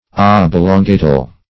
Search Result for " oblongatal" : The Collaborative International Dictionary of English v.0.48: Oblongatal \Ob"lon*ga"tal\, a. Of or pertaining to the medulla oblongata; medullar.